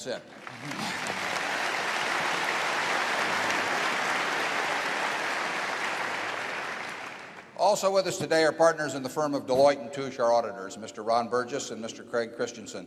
berkshire-meetings